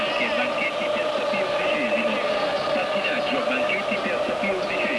Semifinale Coppa dei Campioni